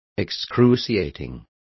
Complete with pronunciation of the translation of excruciating.